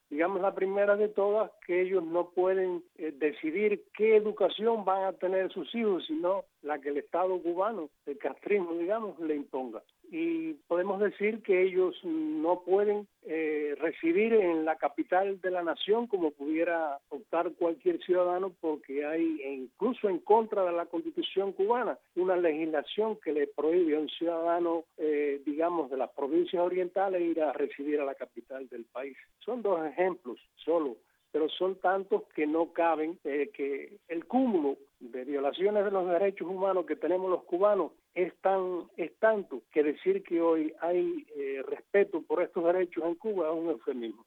(Con entrevistas concedidas a Radio Martí)